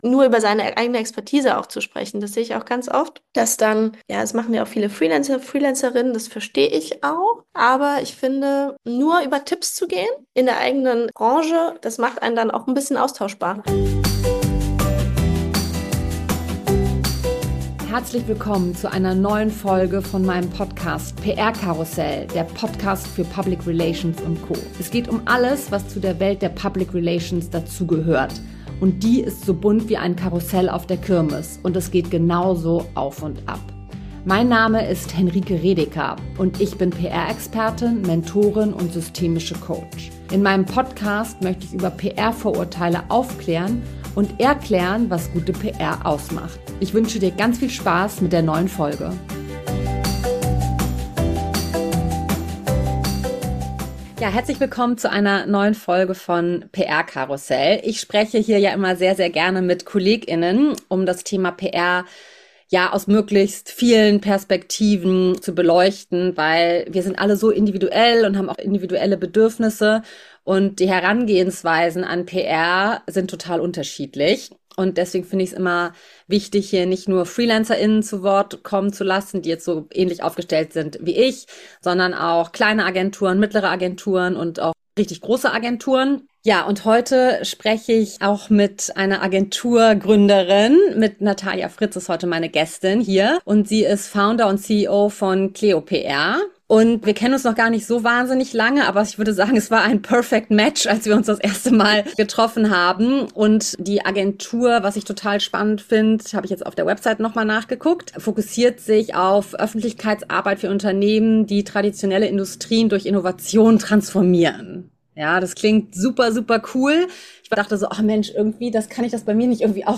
Ein inspirierendes Gespräch über Mut, Haltung und die Kraft klarer Kommunikation.